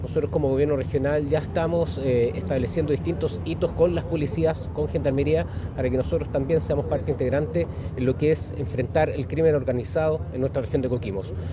En esa línea, el Gobernador Regional, Cristóbal Julia, indicó que se está trabajando con las distintas policías para el combate del crimen,